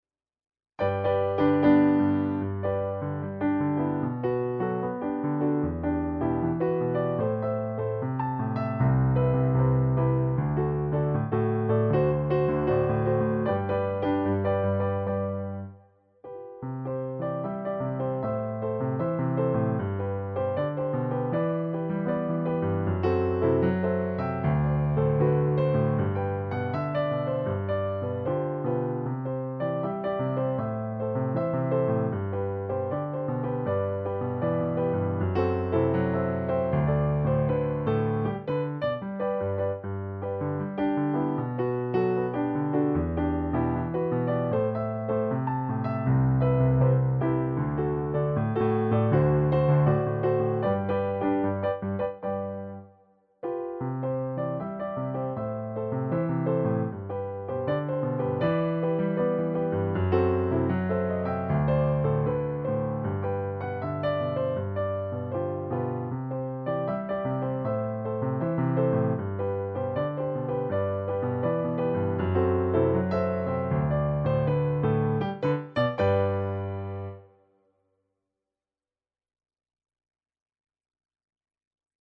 Segue nesse novo projeto, a gravação audiovisual dos arranjo para as 17 músicas do Cordão, para canto coral e piano.
Piano s/ Clique